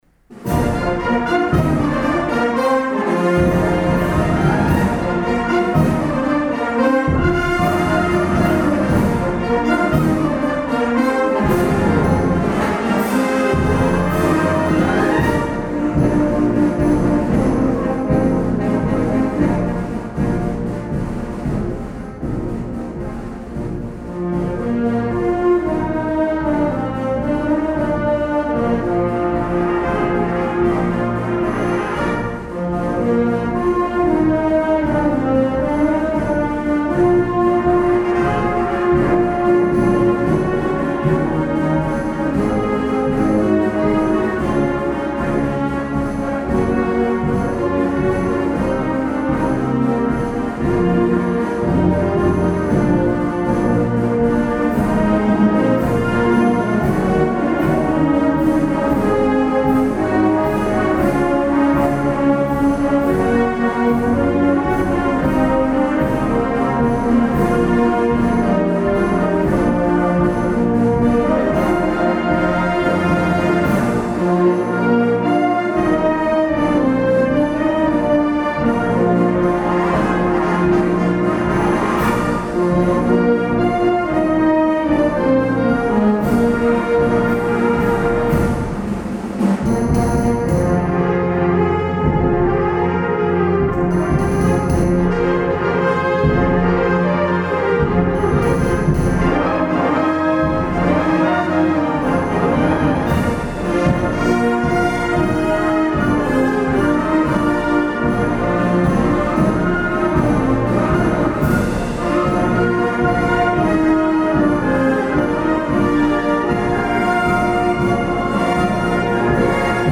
juntament amb Banda de Música de Llucmajor